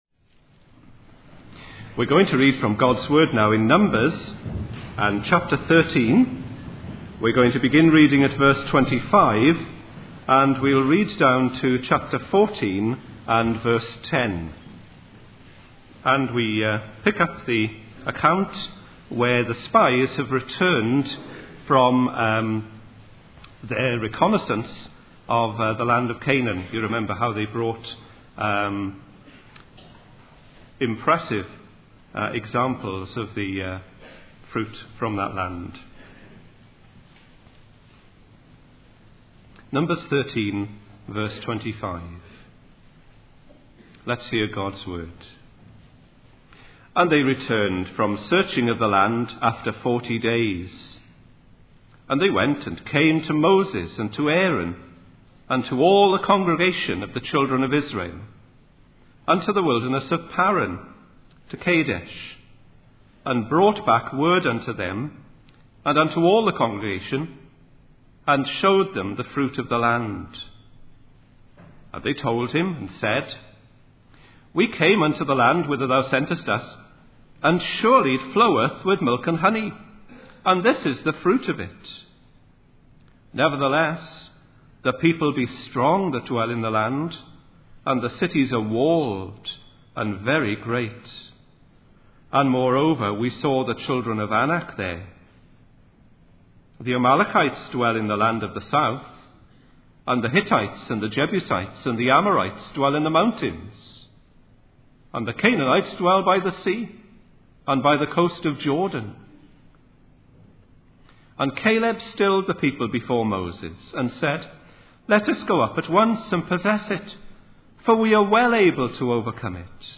In this sermon, the preacher focuses on the example of Caleb from the Bible and how he was able to resist negative peer pressure and follow God fully.